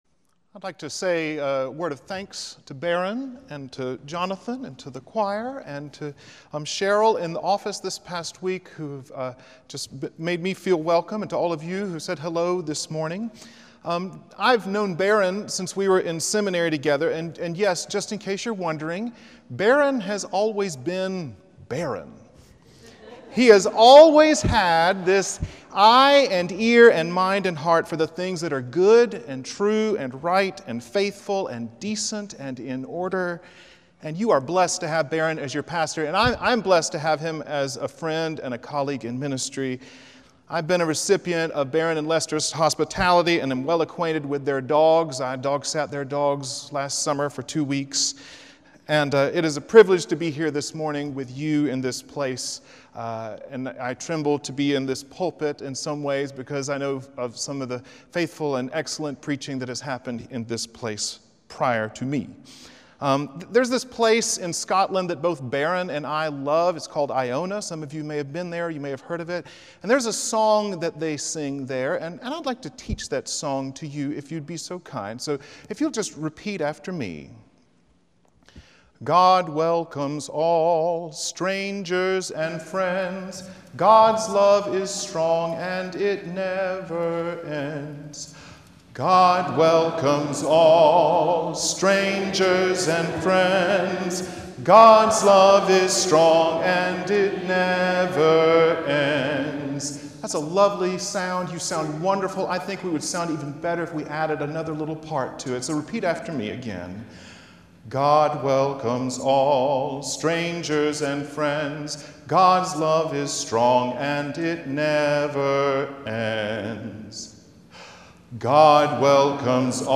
Morningside Presbyterian Church - Atlanta, GA: Sermons: Welcoming Heaven